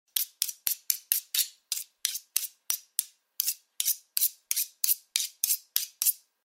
Слушайте онлайн или скачивайте бесплатно резкие, металлические скрежеты и ритмичные движения точильного камня.
Быстрая заточка кухонного ножа